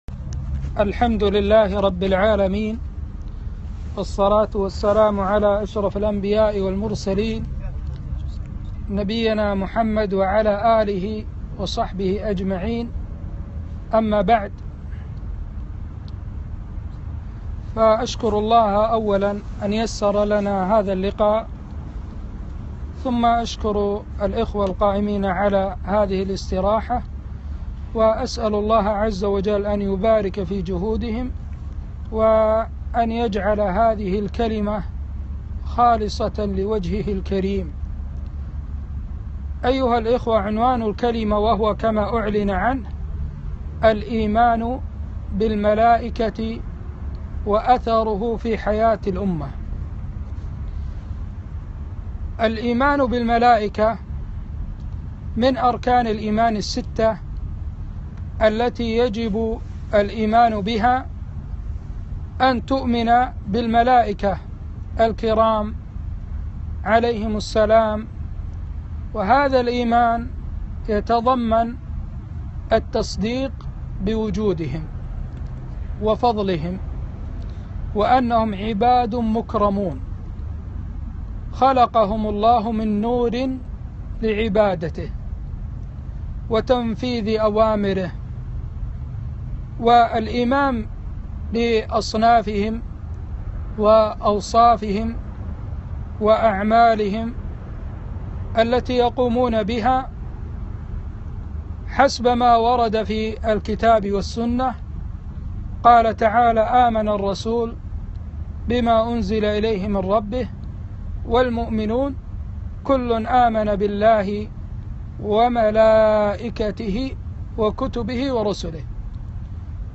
محاضرة - الإيمان بالملائكة وأثره في حياة الأمة